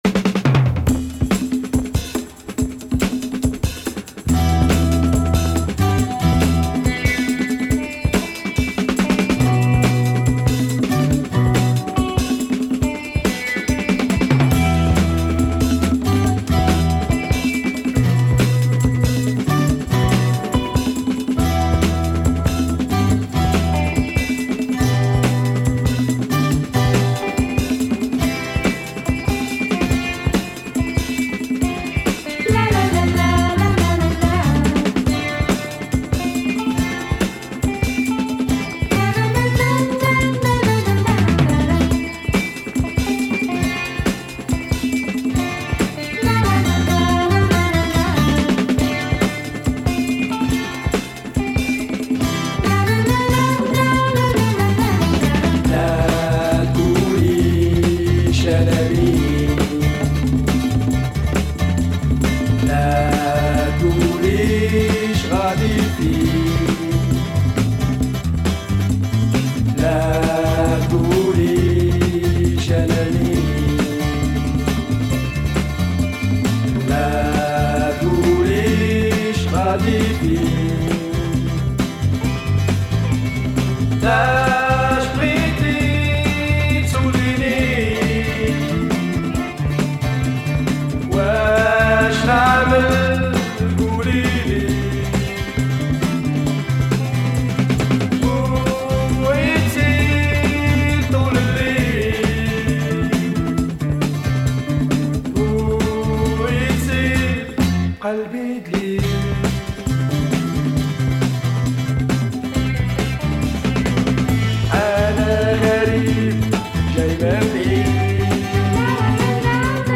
Killer Arabic funk !
cult Algerian duo
groovy proto rai tracks